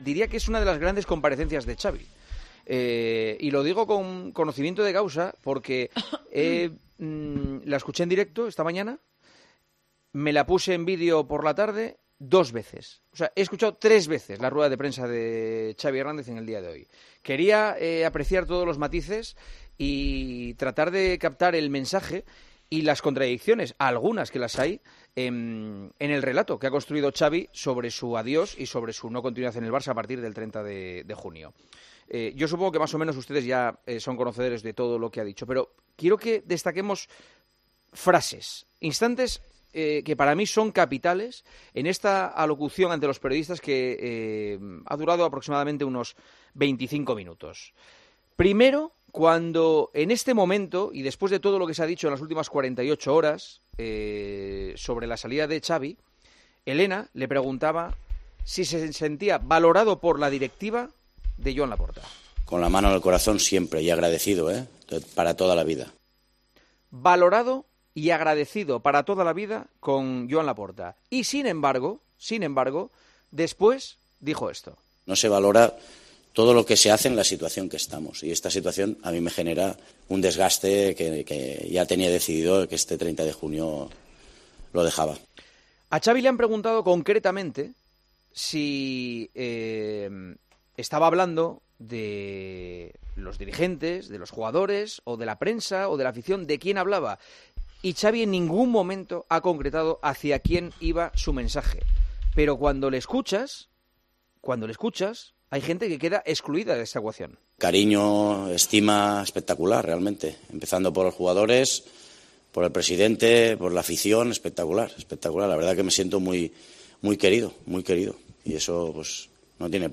El director de El Partidazo de COPE analizó la rueda de prensa de Xavi Hernández de este martes, en la que habló de su decisión de dejar el banquillo azulgrana a final de temporada